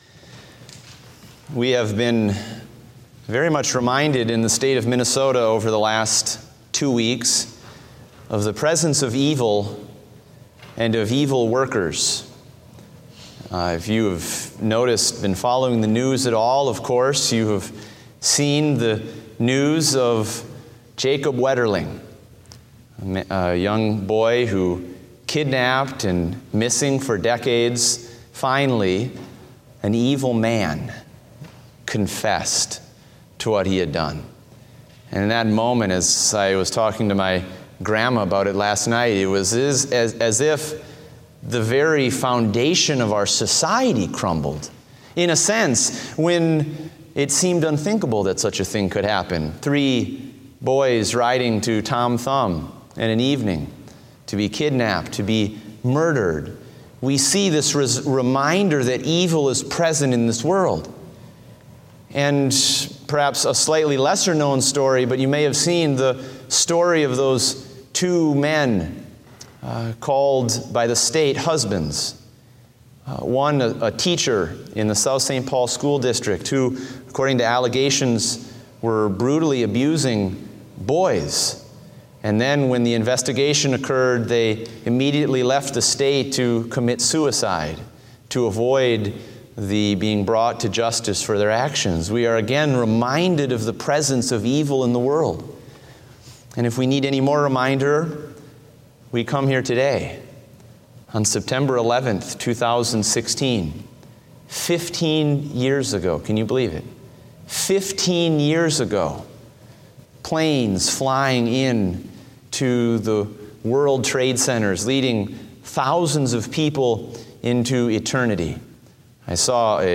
Date: September 11, 2016 (Evening Service)